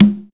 06 CONGA.wav